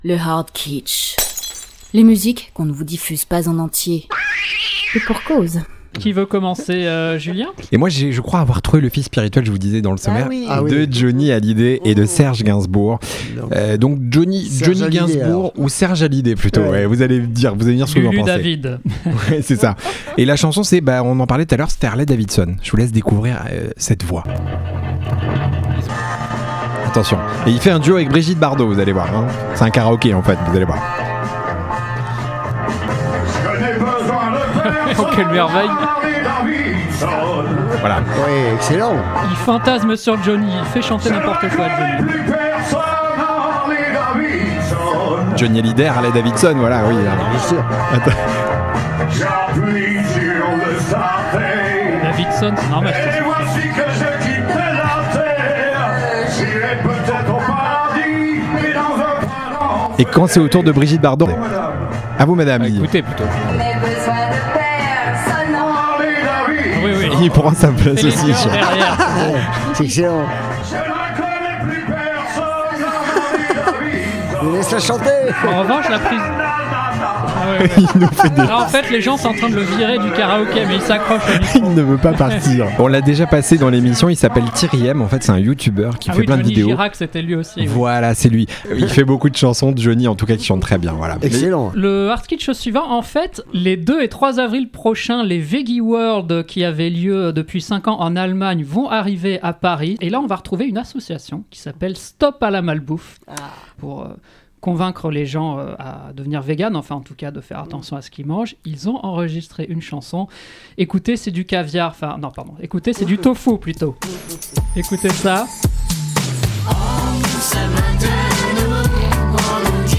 Chaque animateur de « Kitsch et Net » fait découvrir en live à ses petits camarades des extraits musicaux dits « HARDkitschs » (voir ici la définition), qui ne seront pas diffusés en entier…
Savourez à volonté tous ces happenings pleins de surprises, de délires et surtout de gros éclats de rire, et retrouvez aussi des informations sur les chanteurs diffusés…
Attention à vos oreilles…